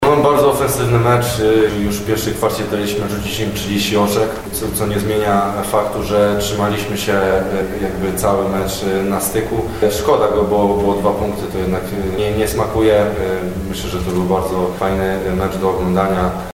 O przebiegu pierwszej kwarty i niewykorzystanej szansy na wygranie całego spotkania wypowiedział się zawodnik